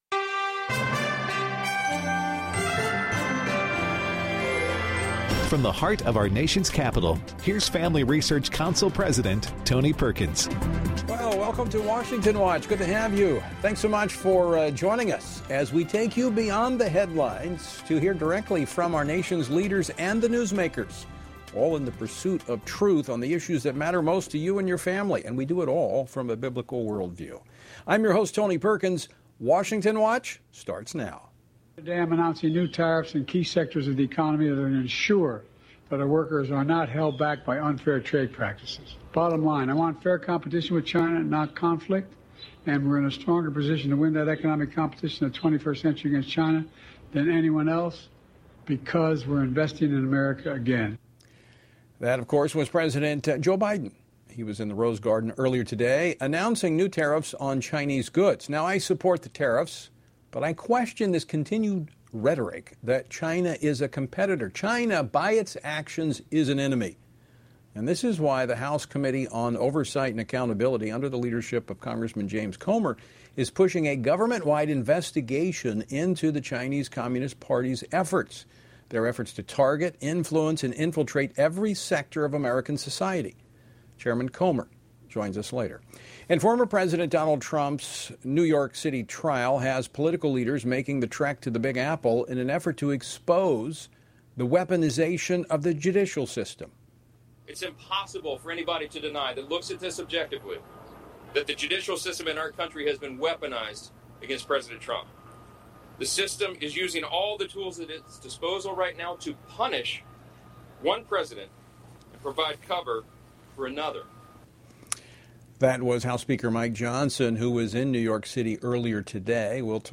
Tommy Tuberville, U.S. Senator from Alabama, discusses the New York City trial against former President Donald Trump and provides an update on Israel’s war against Hamas. Andrew Bailey, Missouri Attorney General, explains why he joined a coalition of Republican Attorneys General opposing the World Health Organization’s proposed Pandemic Preparedness Agreement.